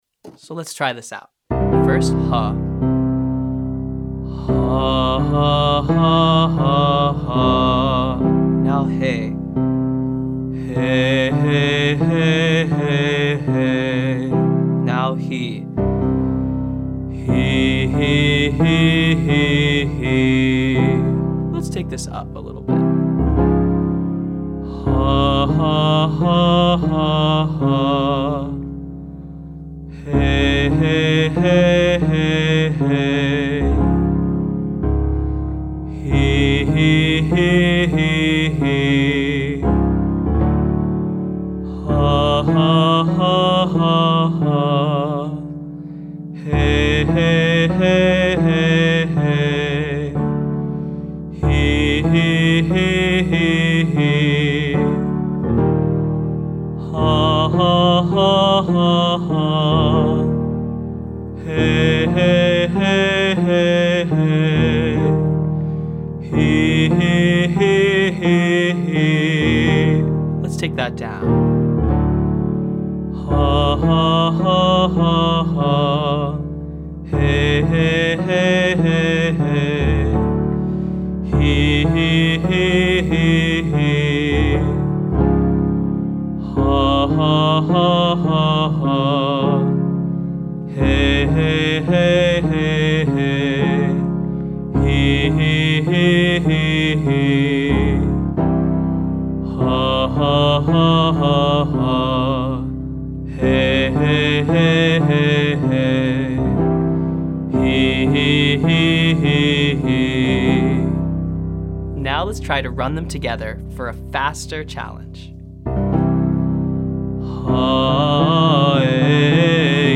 Day 4: Vocal Tension Release - Online Singing Lesson